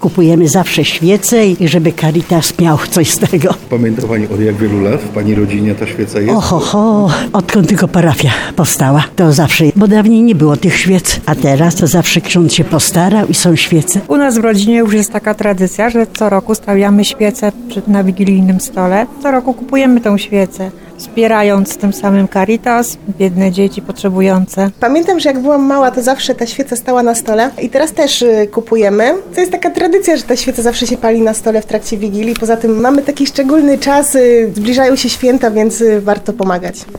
Jak mówili parafianie w Bogumiłowicach, świeca Caritas stała się już tradycją na wigilijnych stołach w ich rodzinach.